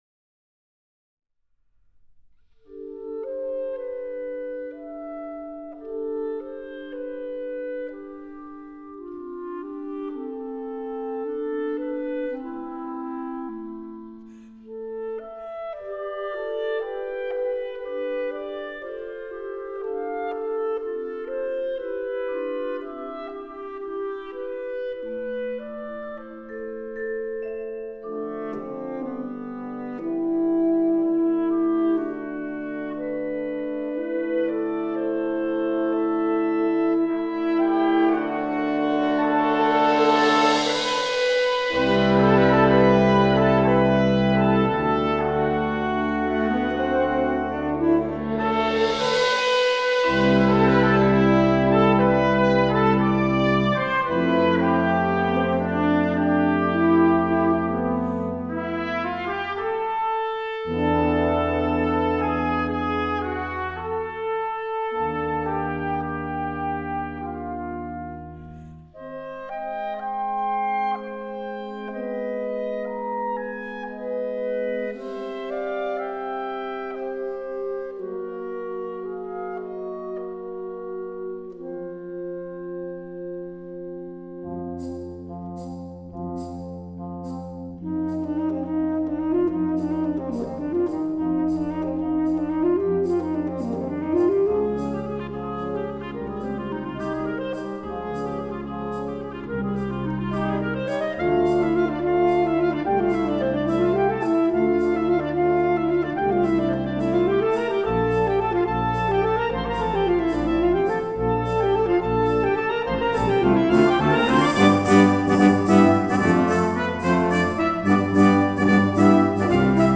Partitions pour ensemble flexible, 7-voix + percussion.